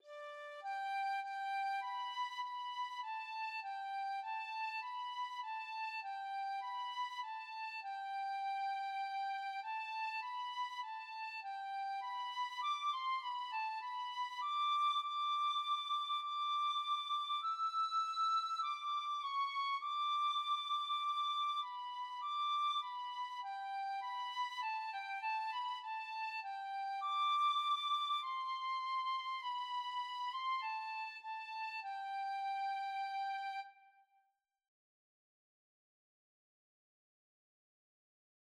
Free Sheet music for Tin Whistle (Penny Whistle)
G major (Sounding Pitch) (View more G major Music for Tin Whistle )
4/4 (View more 4/4 Music)
Tin Whistle  (View more Easy Tin Whistle Music)
Traditional (View more Traditional Tin Whistle Music)